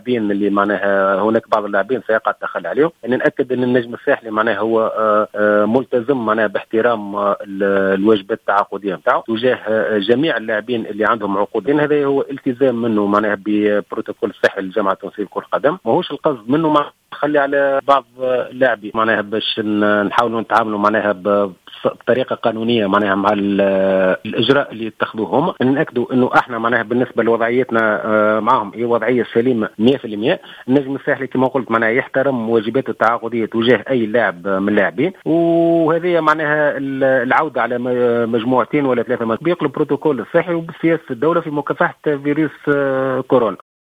في اتصال هاتفي للجوهرة افم